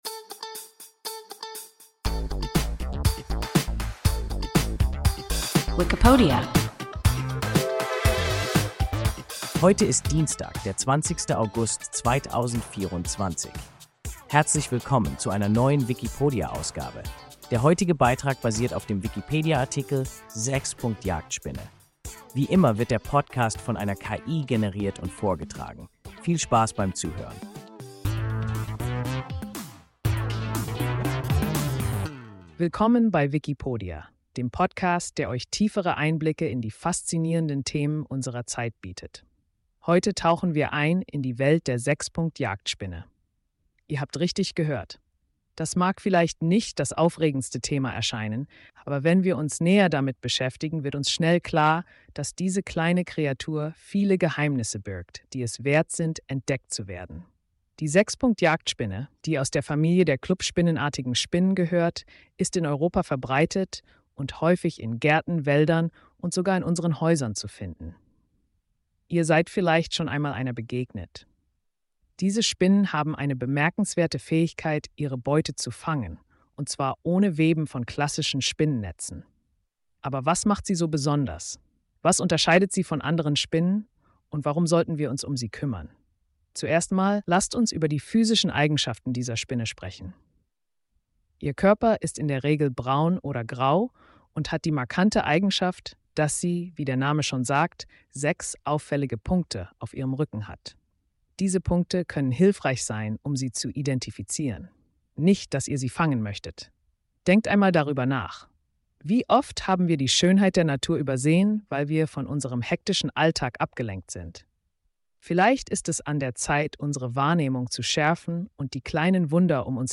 Sechspunkt-Jagdspinne – WIKIPODIA – ein KI Podcast